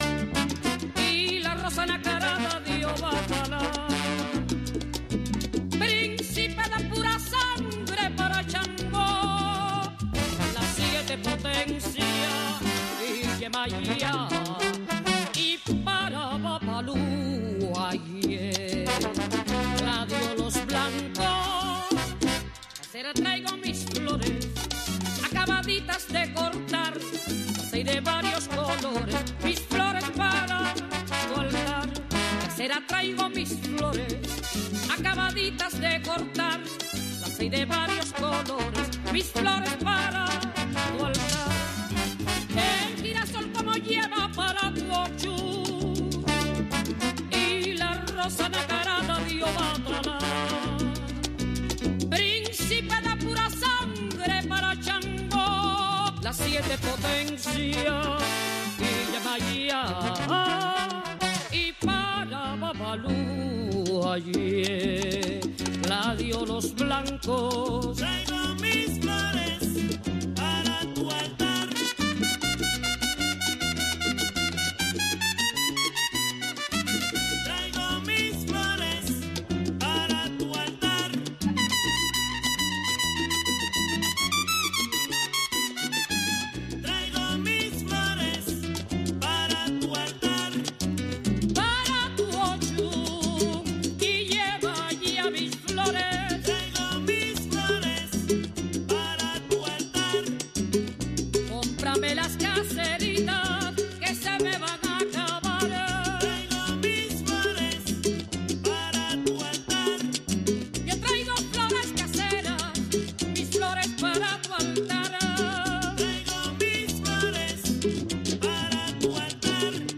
Una hora con temas para el campesino, entrevistas y música.